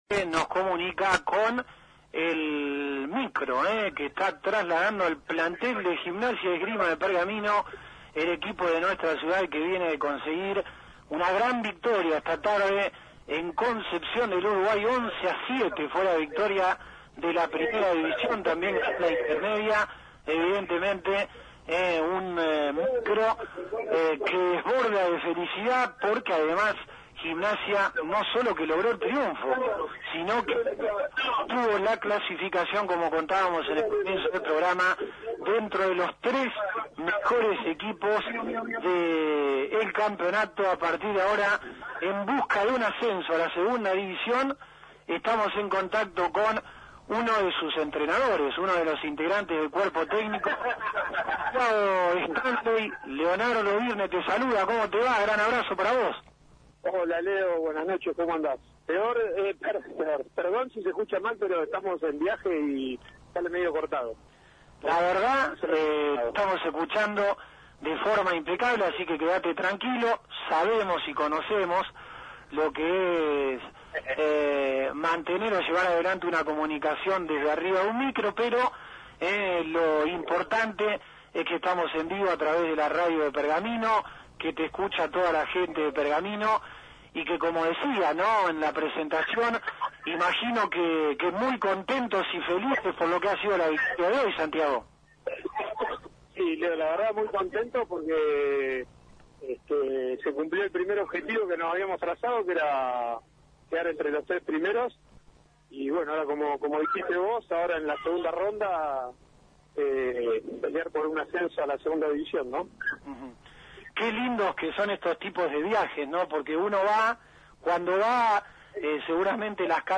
Gimnasia y Esgrima de Pergamino celebra una victoria crucial y una clasificación histórica en el Torneo Regional del Litoral de Rugby, tras vencer esta tarde a Universitario de Concepción del Uruguay por 11 a 7. LT35 Radio Mon Pergamino fue el único medio en obtener declaraciones exclusivas del plantel en su viaje de regreso.
El entusiasmo y la alegría dominan el ambiente en el micro que traslada a los jugadores de vuelta a Pergamino.